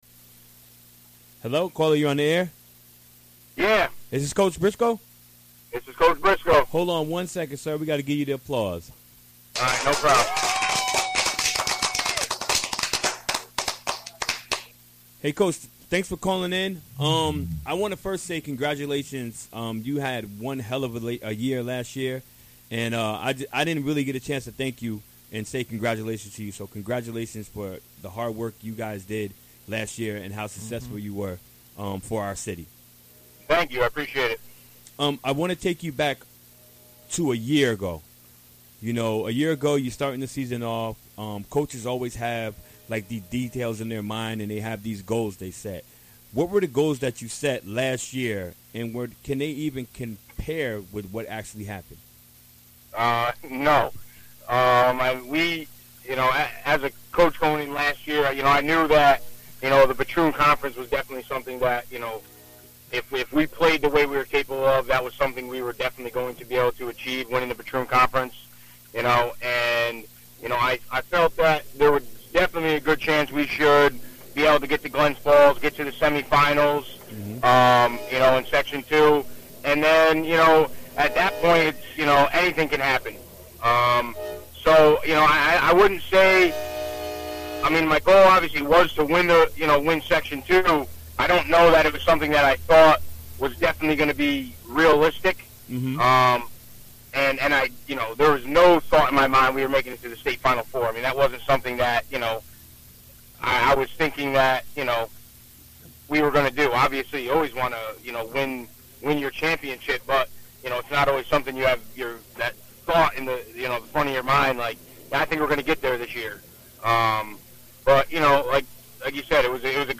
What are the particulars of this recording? Recorded during the WGXC Afternoon Show Wednesday, November 30, 2016.